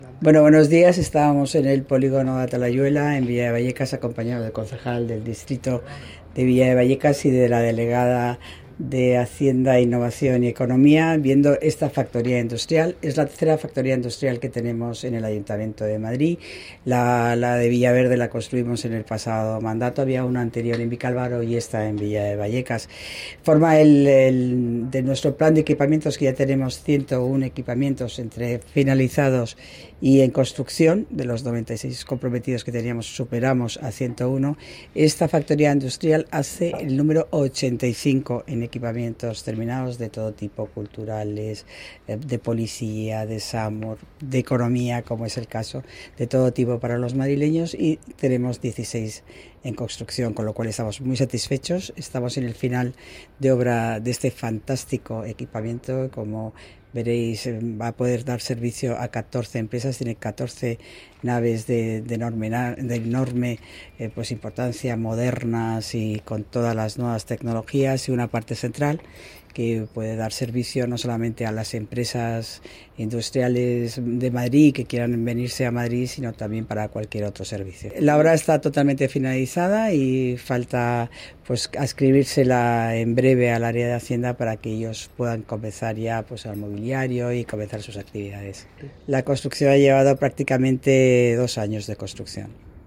La delegada de Obras y Equipamientos, Paloma García Romero:
AUDIOGarcia-Romero.factoria-industrial-de-La-Atalayuela.mp3